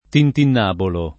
vai all'elenco alfabetico delle voci ingrandisci il carattere 100% rimpicciolisci il carattere stampa invia tramite posta elettronica codividi su Facebook tintinnabulo [ tintinn # bulo ] (raro tintinnabolo [ tintinn # bolo ]) s. m.